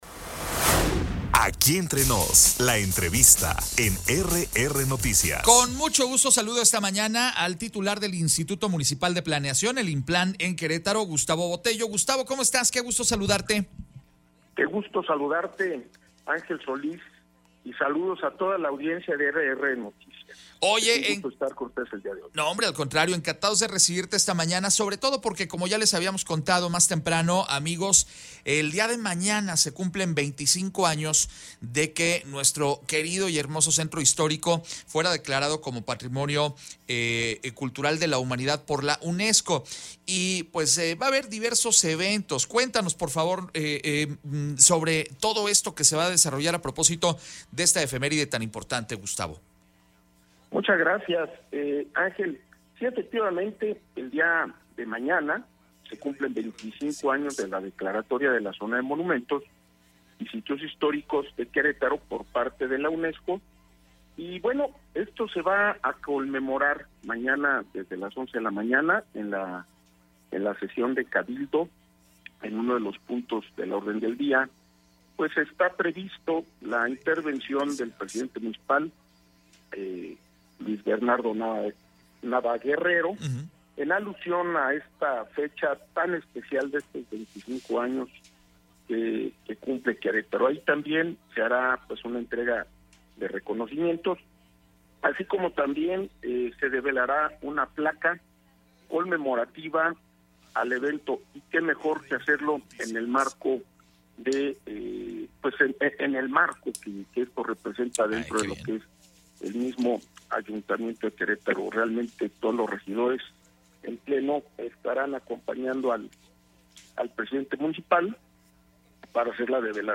EntrevistasOpiniónPodcast